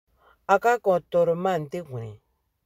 Lecture et prononciation
Lisez les phrases suivantes à haute voix, puis cliquez sur l'audio pour savoir si votre prononciation est la bonne.